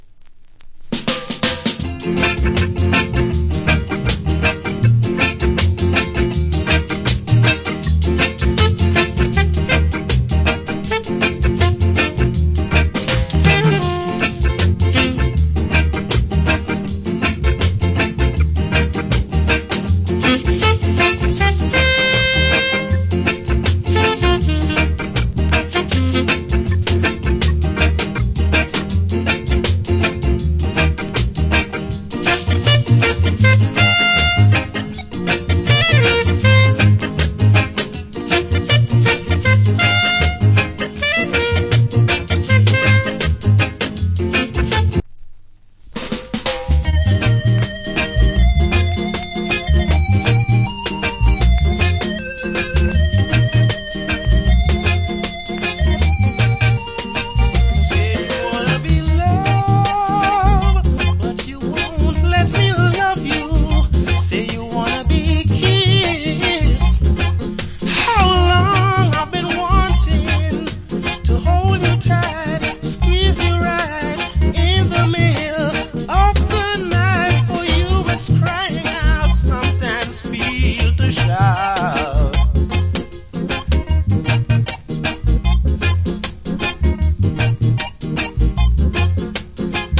Wicked Early Reggae Inst.